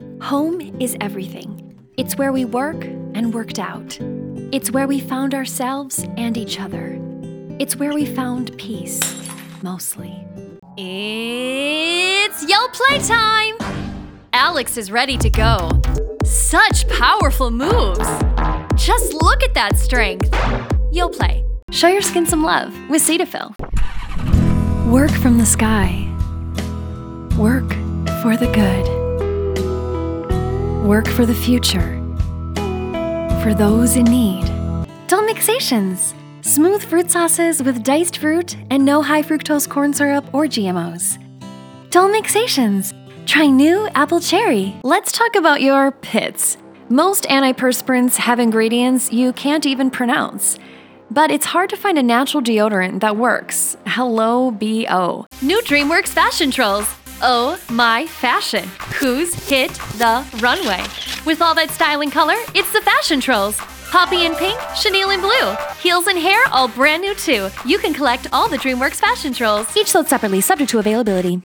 Final-Voice-Over-Reel-updated-91920.wav